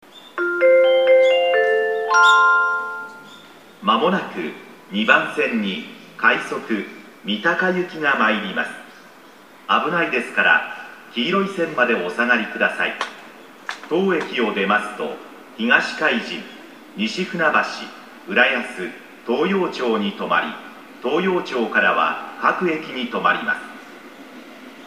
駅放送
2014年2月25日頃より接近メロディ付きの新放送に切り替わりました（到着放送は3月9日頃追加）。